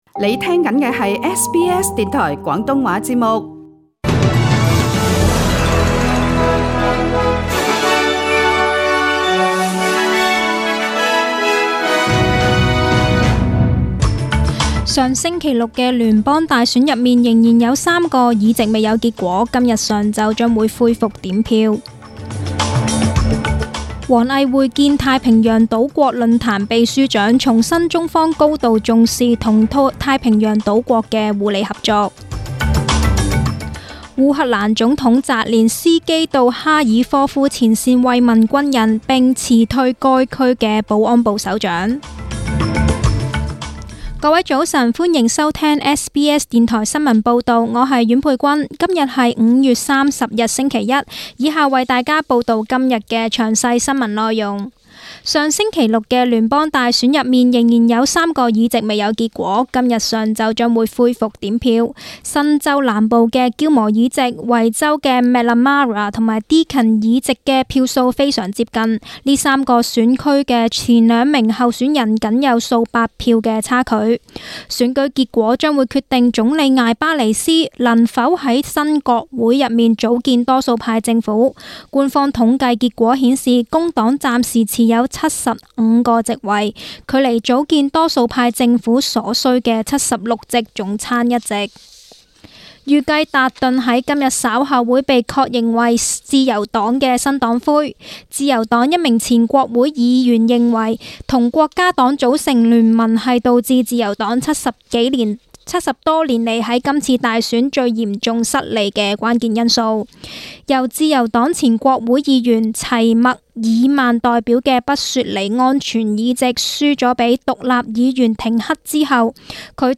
请收听本台为大家准备的详尽早晨新闻。
SBS 廣東話節目中文新聞 Source: SBS Cantonese